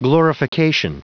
Prononciation du mot glorification en anglais (fichier audio)
glorification.wav